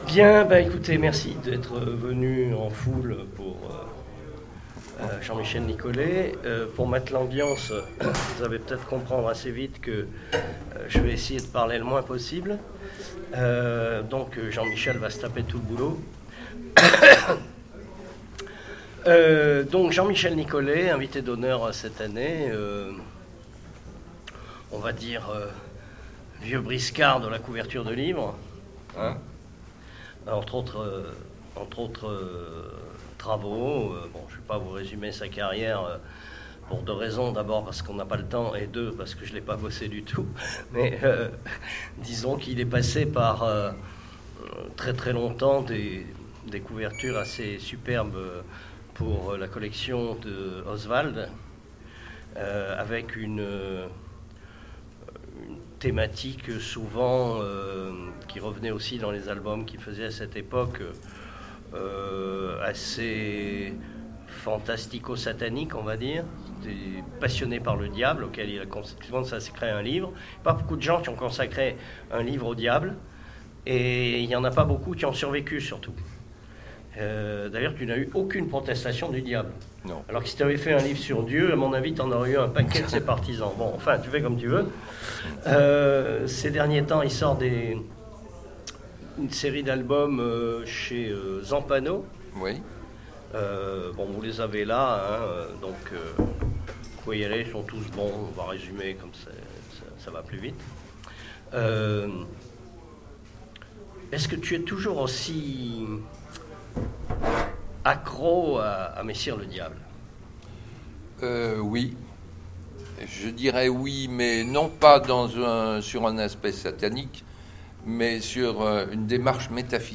Rencontres de l’Imaginaire 2017 : Conférence